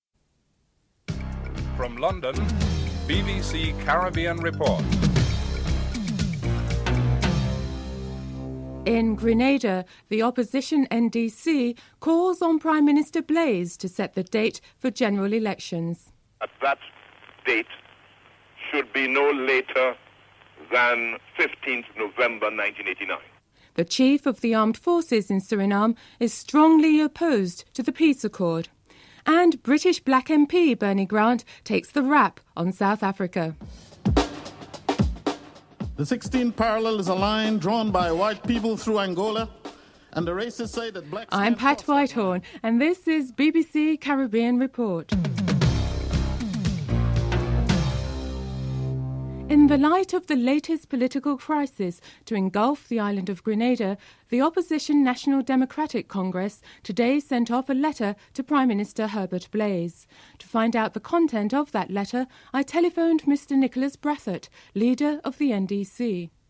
1. Headlines (00:00-00:42)
4. Financial News (07:48-9:25)